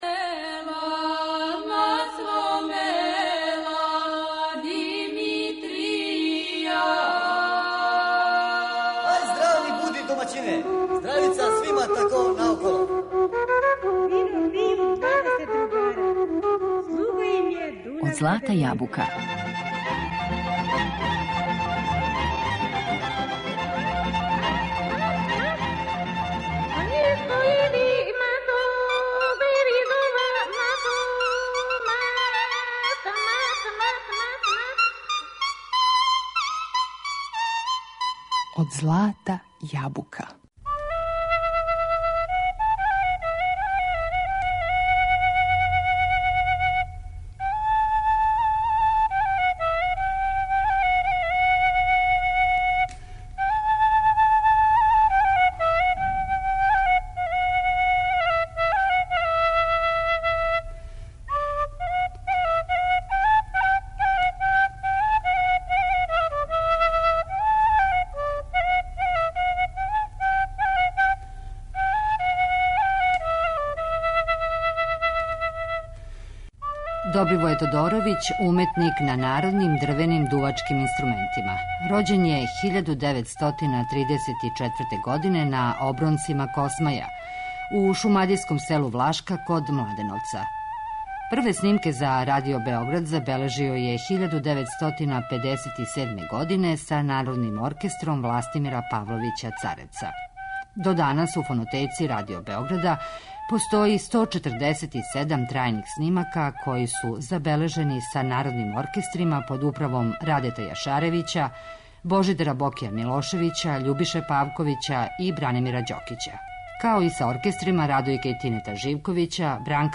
представићемо музички портрет уметника на традиционалним народним дрвеним дувачким инструментима